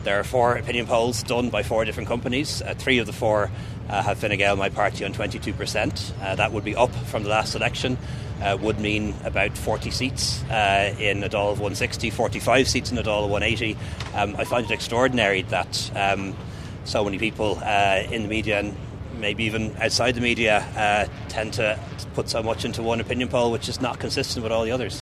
Speaking this evening Leo Varadkar defended his party’s performance in the polls: